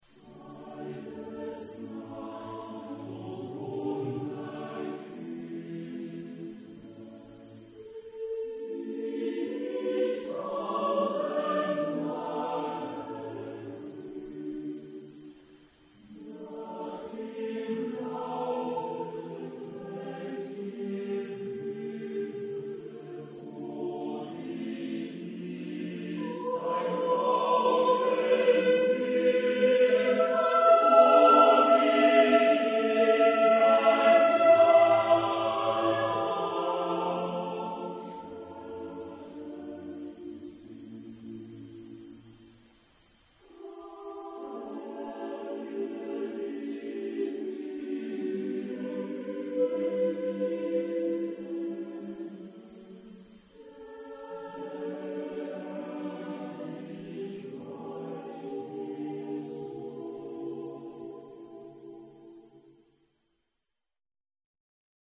Genre-Style-Forme : Romantique ; Profane ; Lied
Type de choeur : SATB  (4 voix mixtes )
Tonalité : ré majeur